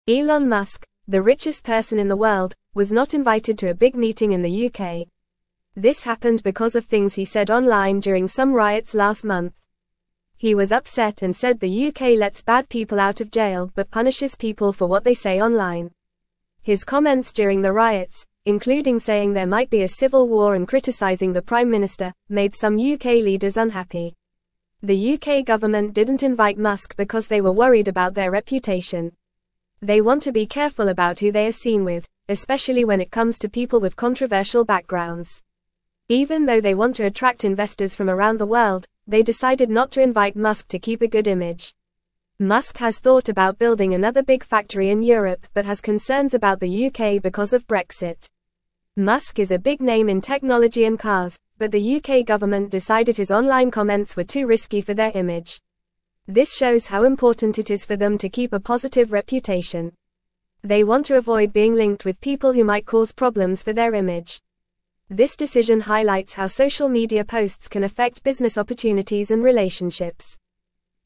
Fast
English-Newsroom-Lower-Intermediate-FAST-Reading-Elon-Musk-not-invited-to-UK-meeting-upset.mp3